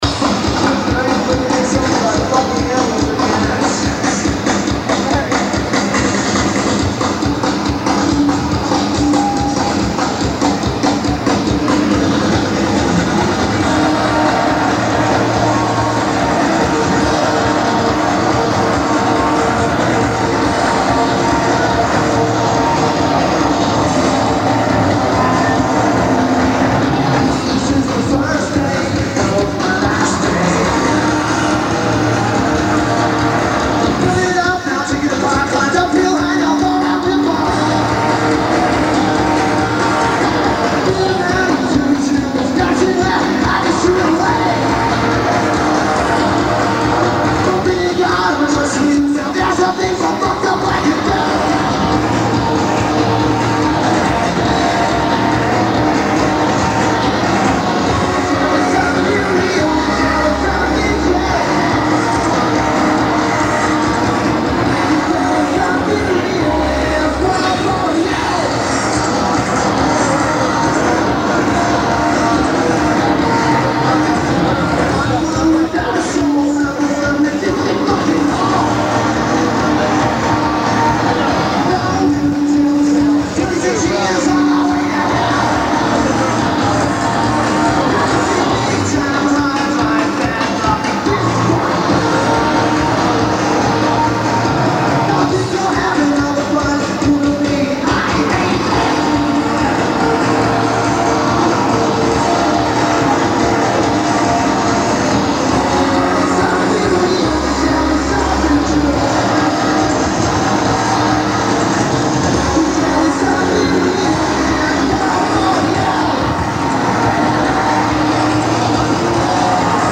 Shoreline Amphitheater
Decent tape.
Could be because this tape is over 20 years old.